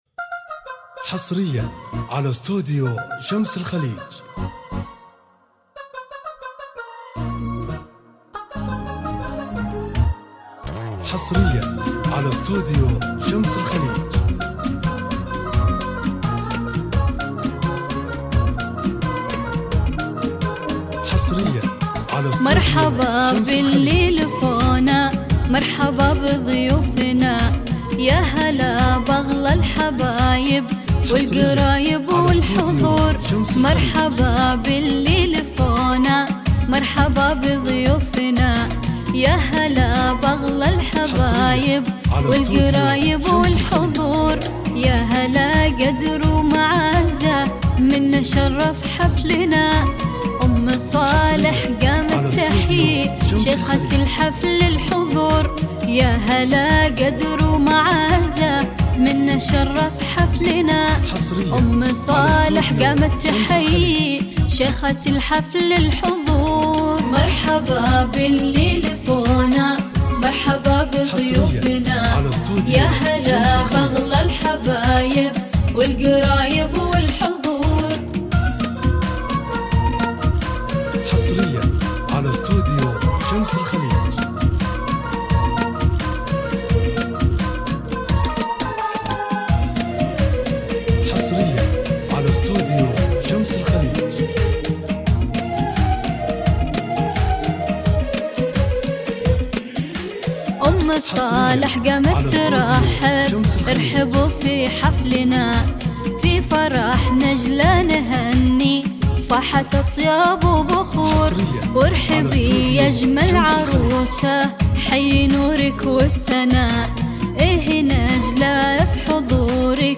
زفات بدون موسيقى